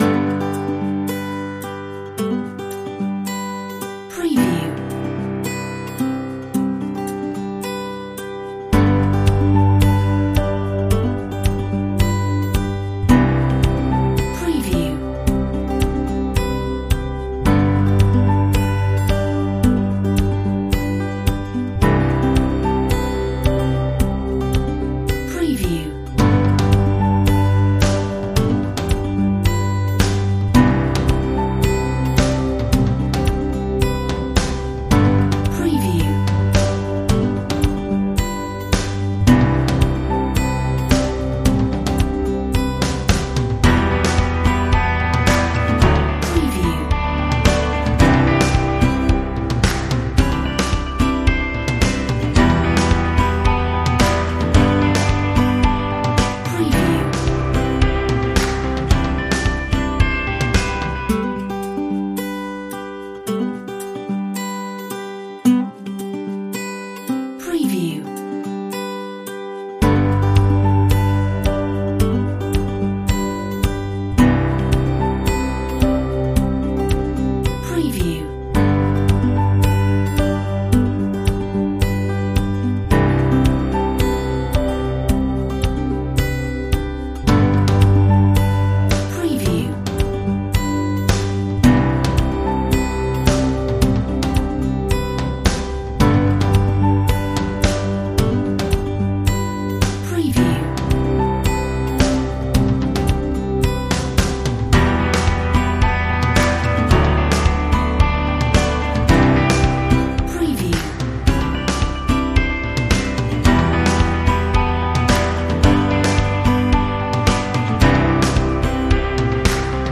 Inspiring acoustic music track